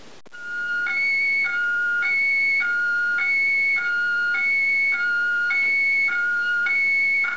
However, he also knows a couple of phrases: (click to hear Wolfgang speak)
Wolfgang screaming for help
screaming.wav